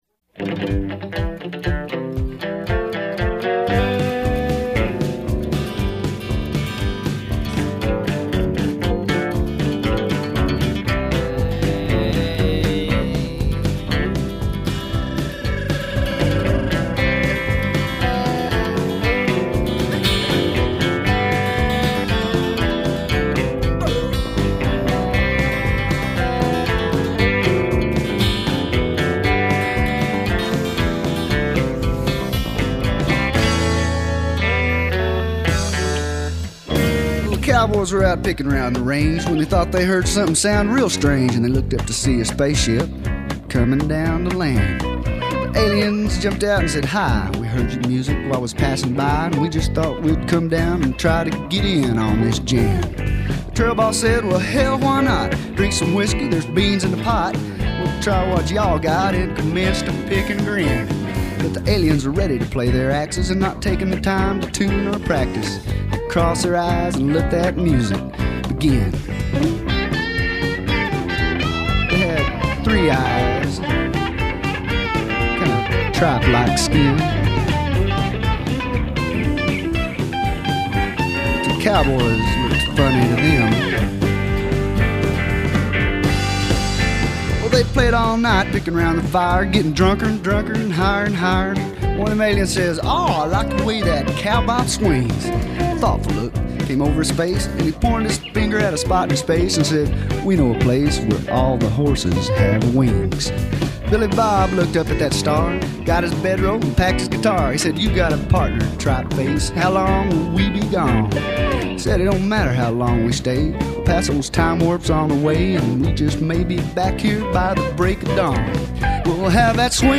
The Fiddle